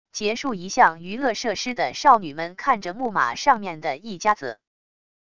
结束一项娱乐设施的少女们看着木马上面的一家子wav音频